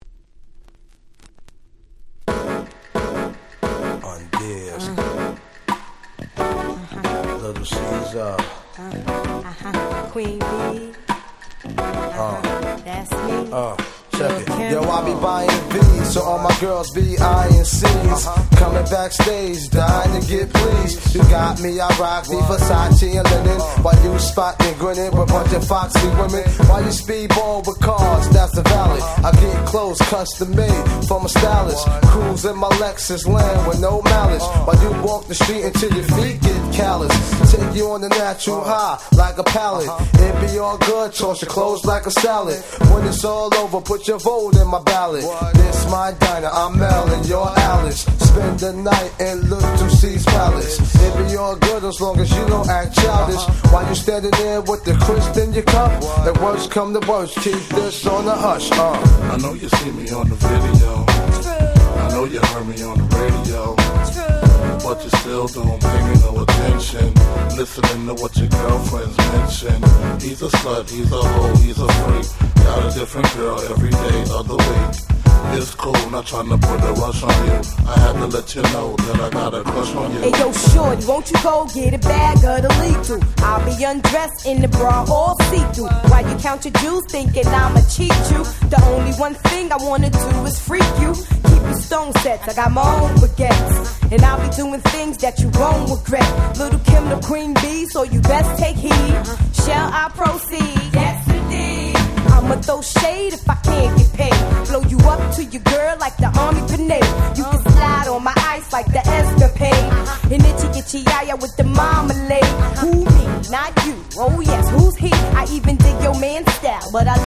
96' Brooklyn Classic !!